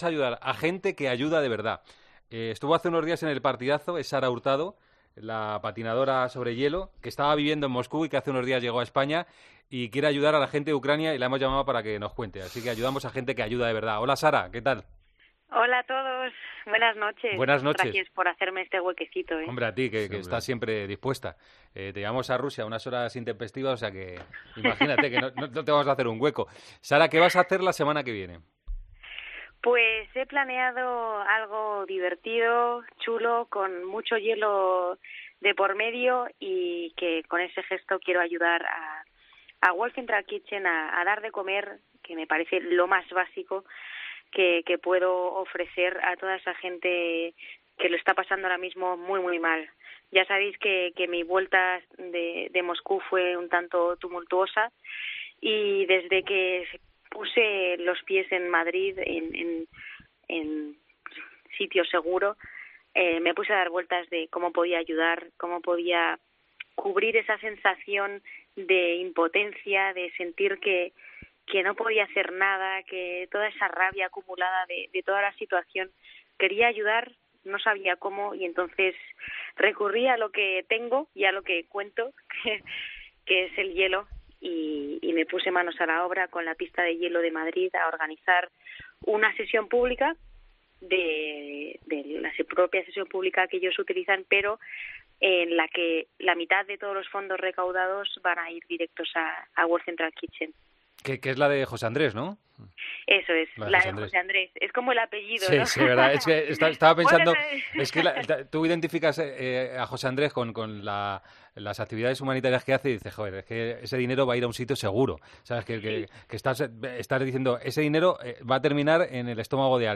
La patinadora española volvió hace unos días de Moscú y nos cuenta en El Partidazo de COPE el proyecto que tiene junto a World Central Kitchen para ayudar a Ucrania.